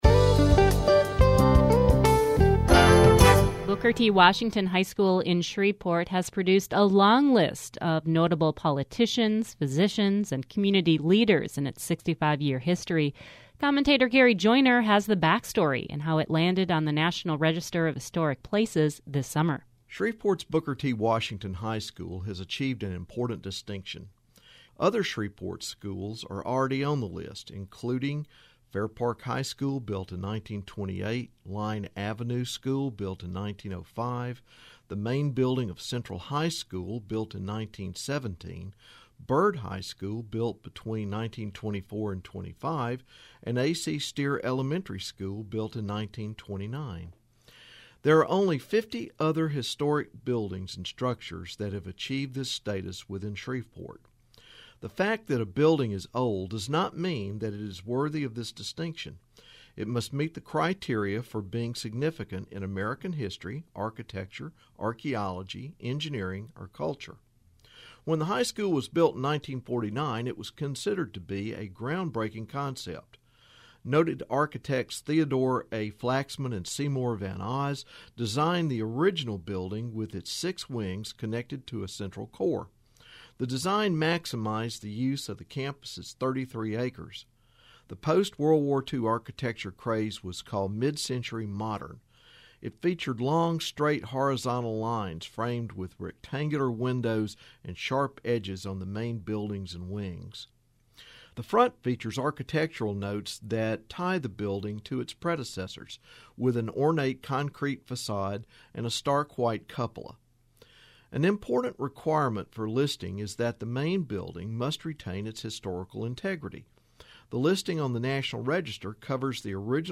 History Matters #431 - Booker T. Washington High School commentary